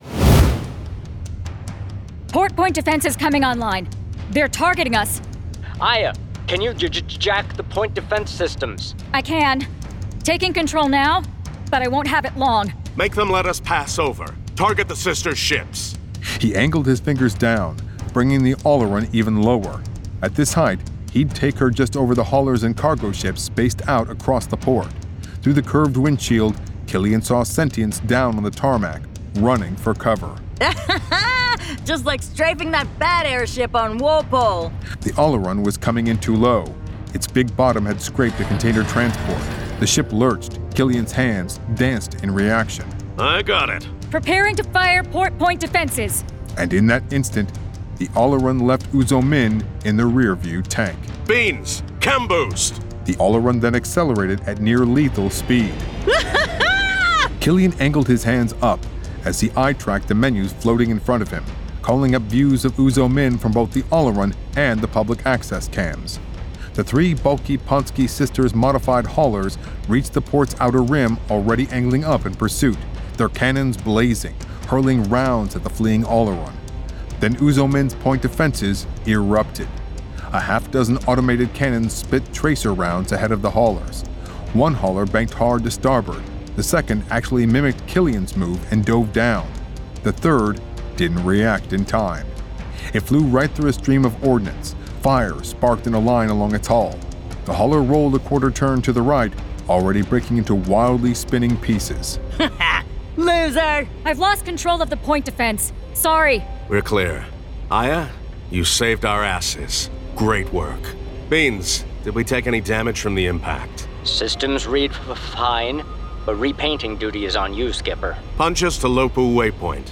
Full Cast. Cinematic Music. Sound Effects.
[Dramatized Adaptation]
Genre: Science Fiction